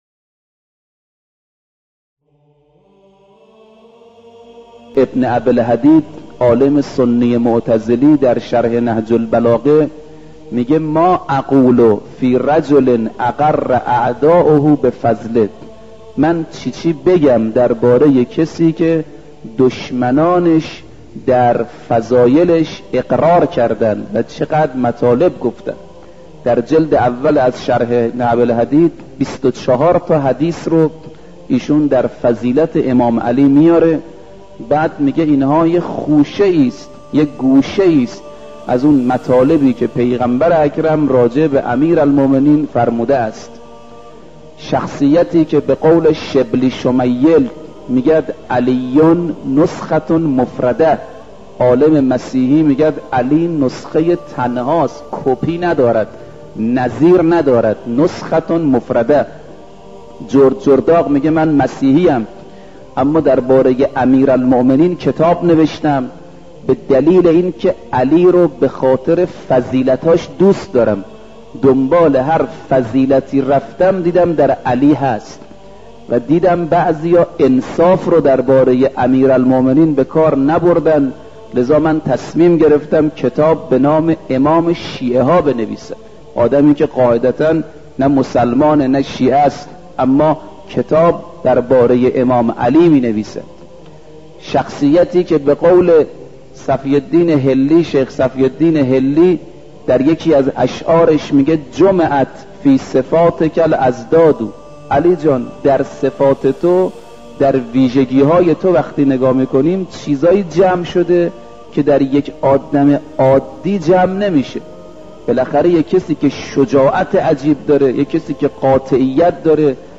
مجموعه پادکست «قطره قطره آسمان» با هدف بیان فضائل امیرالمومنین(ع) با کلام اساتید بنام اخلاق به کوشش ایکنا گردآوری و تهیه شده است، که سی‌وششمین قسمت این مجموعه با کلام حجت‌الاسلام رفیعی با عنوان « علی (ع)؛ آیینه تمام‌نمای فضائل » تقدیم مخاطبان گرامی ایکنا می‌شود.